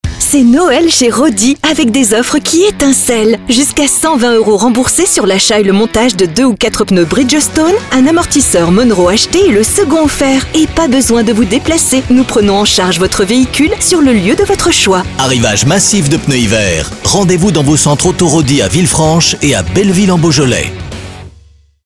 Comédienne voix off féminine - adolescente, jeune, âge moyen, mûre...
Sprechprobe: Werbung (Muttersprache):